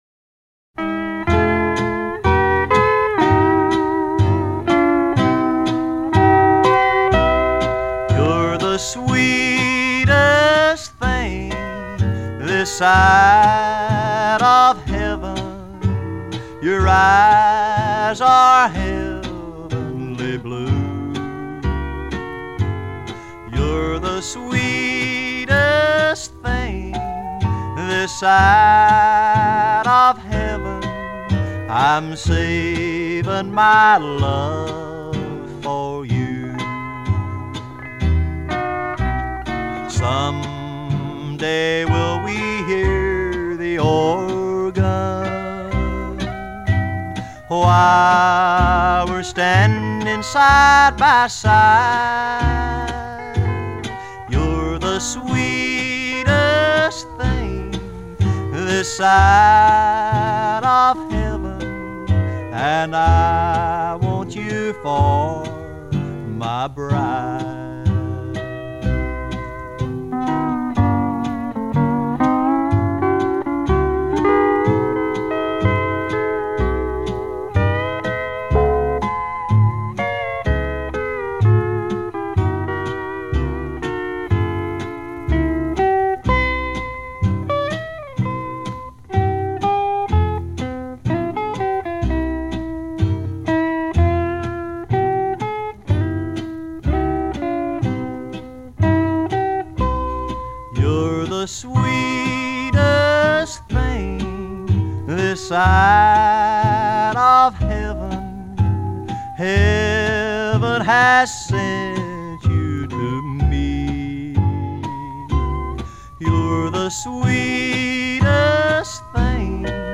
американский кантри-певец.